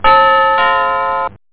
DoorGong1.mp3